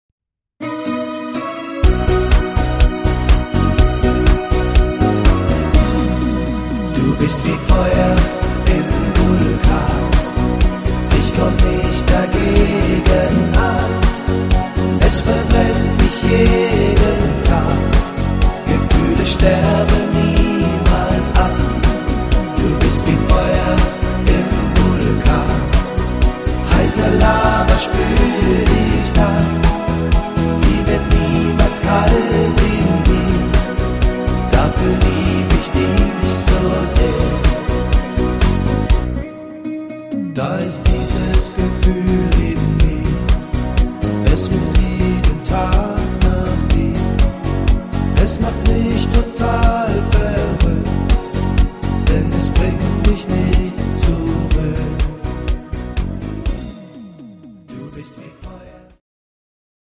Playback abmischen  Playbacks selbst abmischen!
Rhythmus  Discofox
Art  Deutsch, Schlager 2010er